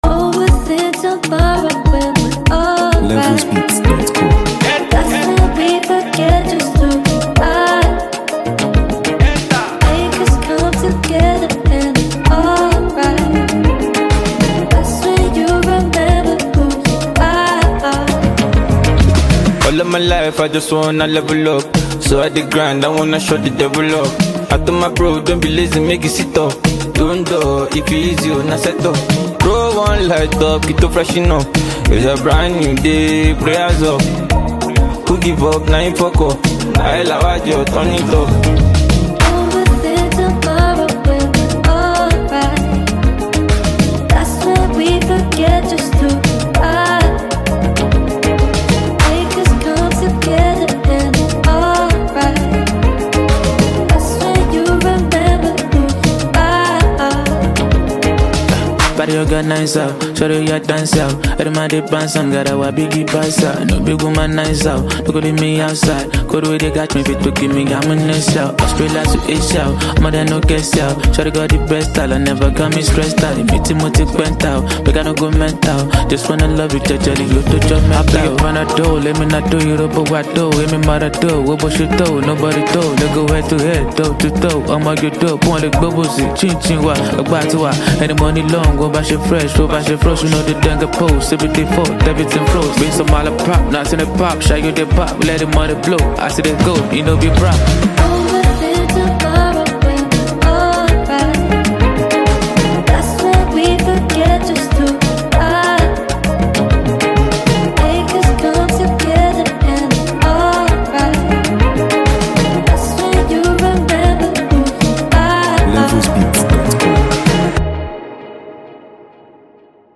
glides on a smooth, confident rhythm
delivering his signature catchy melodies
astounding, sizzling vocals